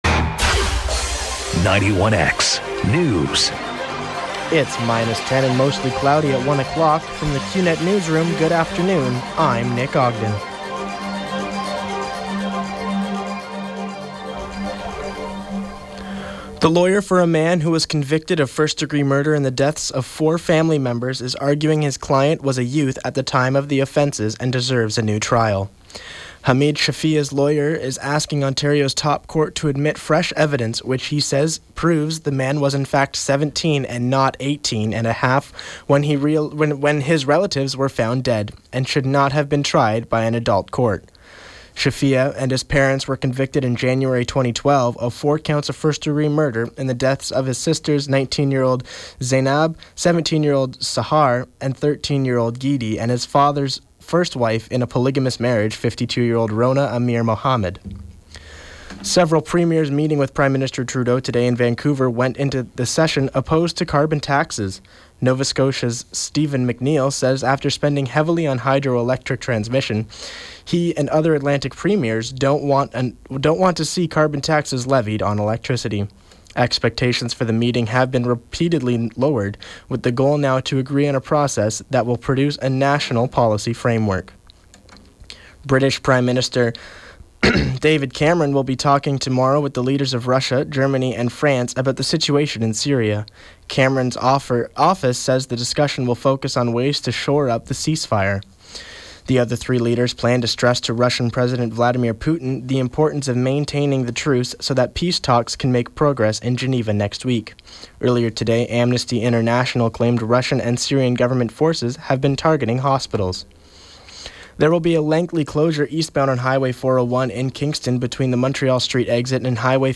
91X newscast – Thursday, March 3, 2016, 1 p.m.